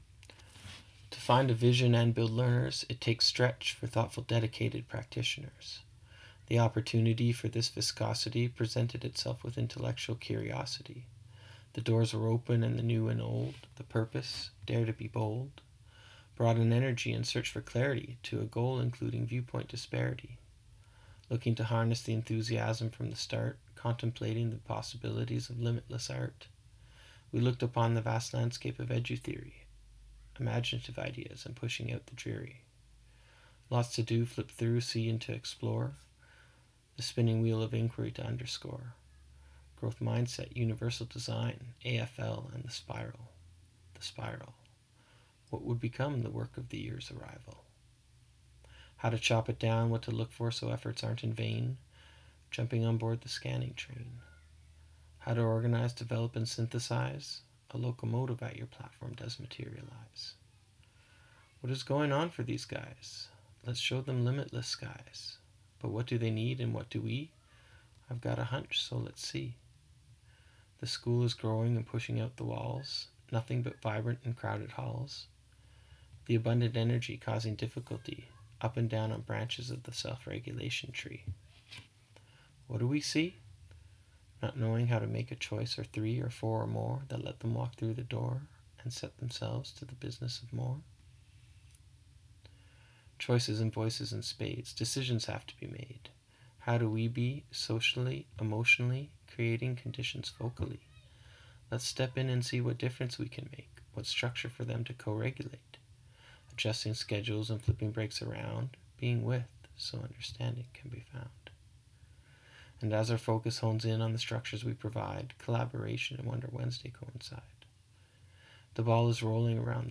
I am trying out including some audio on my blog and also daring greatly with the spoken word recording.